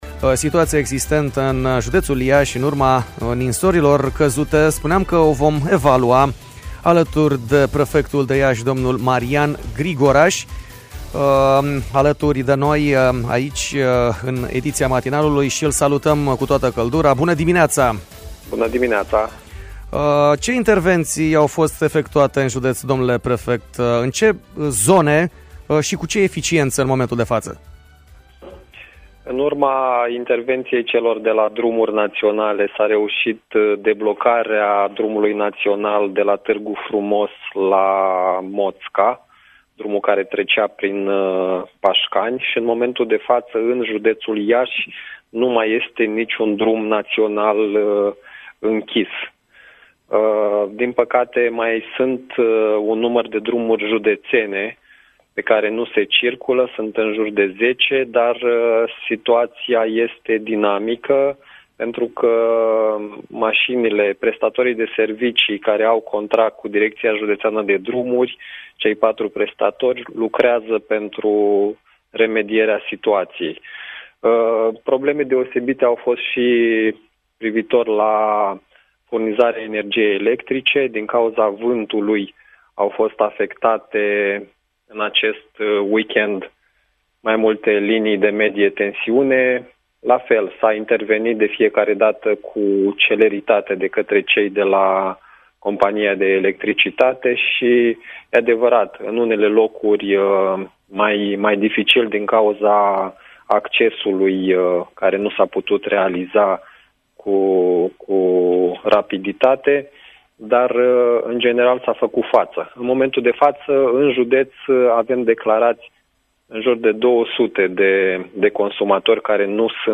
Prefectul de Iași, Marian Grigoraș, ÎN DIRECT la Radio Iași – Tema Zilei